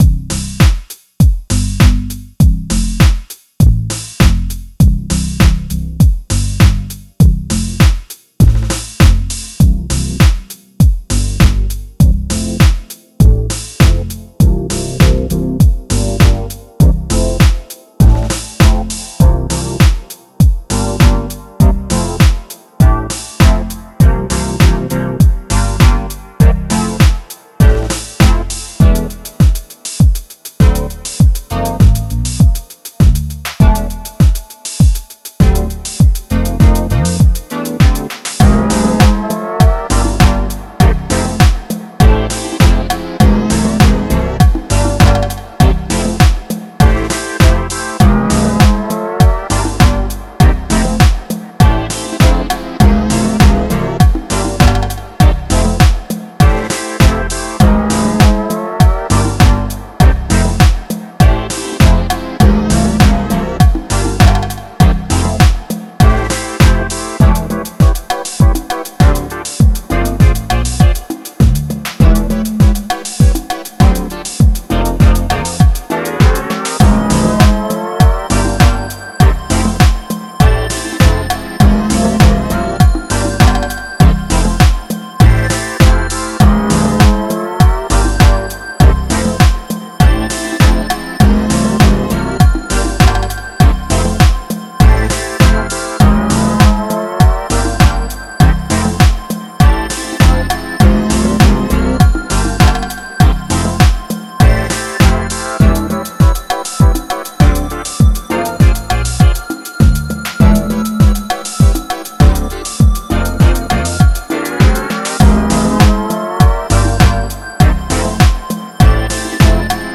Genre Disco
Human voices vocals: Omnisphere synth VST
tempo changed to 103bpm